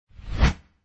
whoosh.mp3